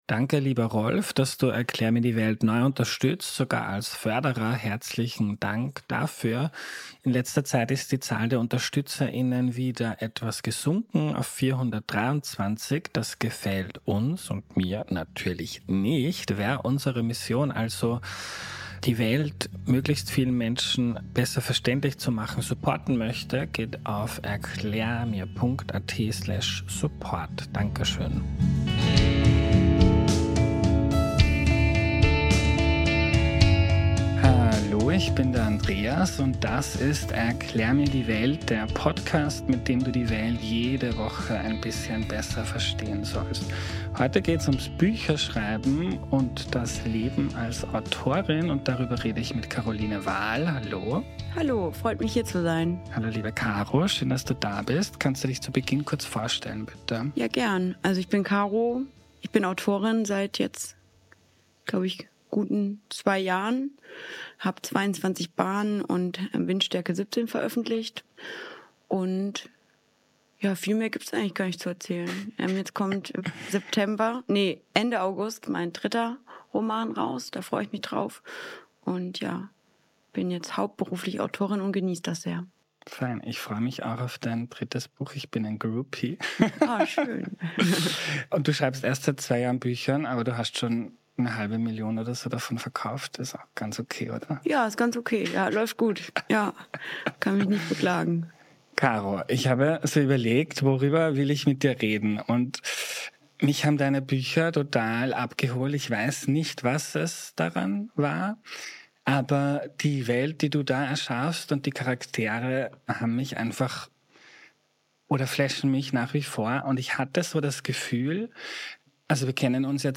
Ein persönliches Gespräch über Bücher, Kreativität und ein gutes Leben.